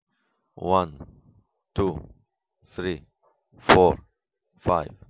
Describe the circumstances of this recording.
windows-sound-recorder-clean.wav